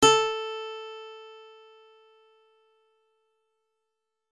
単音のギター音を読み込ませてやり、バッファの複製、パン・周波数の変更を行ってやるとこんな風にジャラーンとコード弾いたみたいになるんですねえ。周波数指定が適当なので音痴なのは愛嬌。
guitar1.mp3